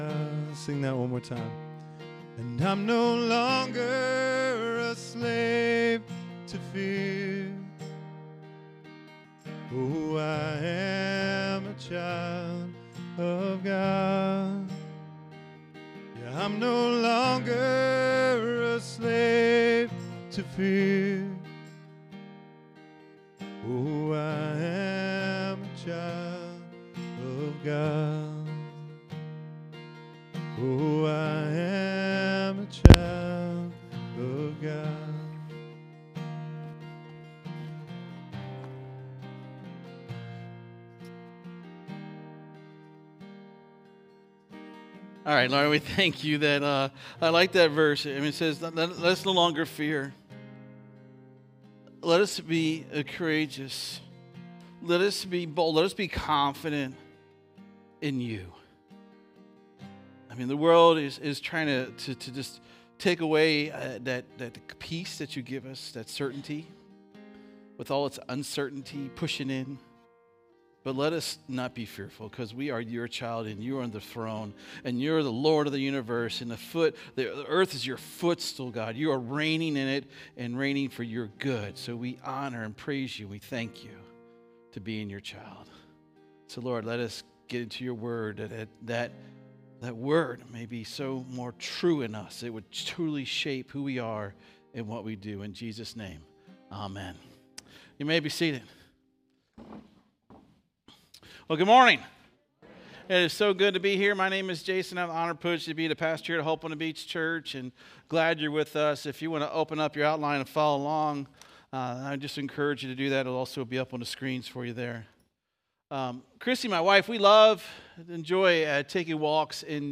Download Download Sermon Notes CLICK HERE FOR NOTES 241103.pdf SERMON DESCRIPTION This week, we will explore the powerful message from Paul's letter to the churches in Galatia.